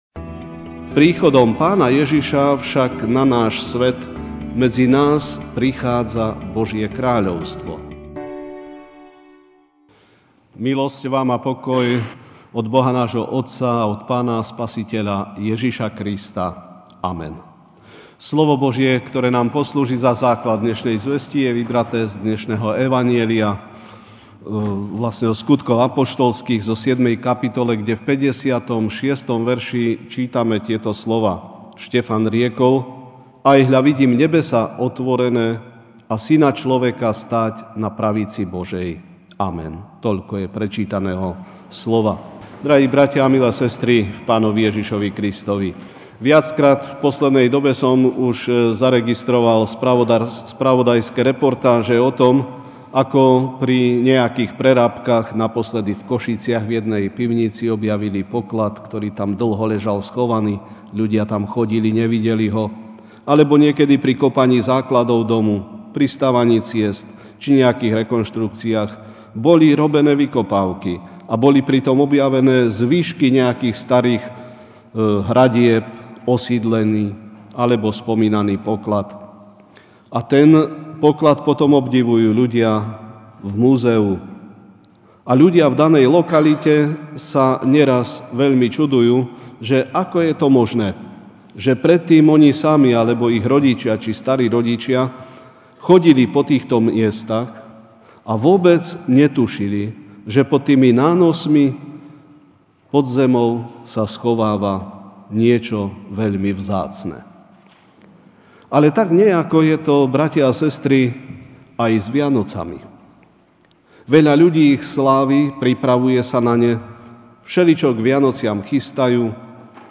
dec 26, 0208 Pôvodný význam Vianoc a ich symbolov MP3 SUBSCRIBE on iTunes(Podcast) Notes Sermons in this Series 2.sviatok vianočný: Pôvodný význam Vianoc a ich symbolov (Sk 7, 56) i riekol: Ajhľa, vidím nebesá otvorené a Syna človeka stáť na pravici Božej.